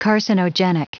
Prononciation audio / Fichier audio de CARCINOGENIC en anglais
Prononciation du mot : carcinogenic